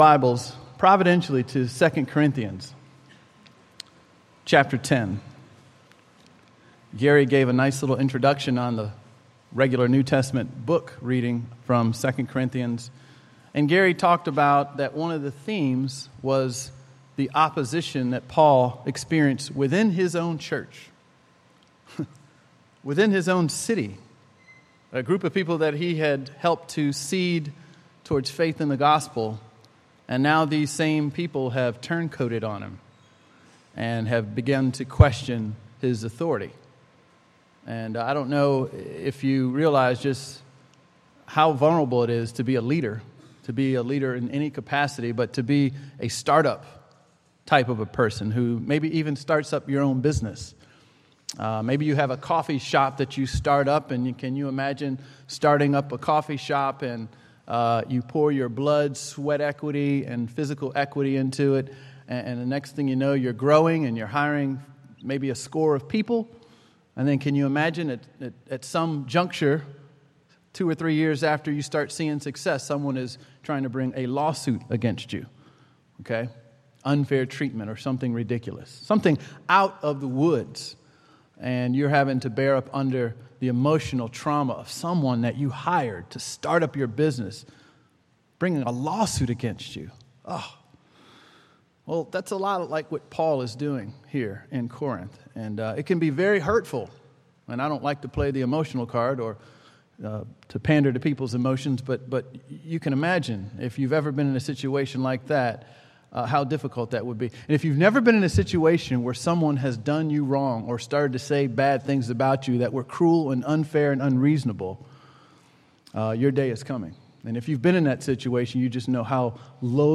GASundaySermon7August2016.mp3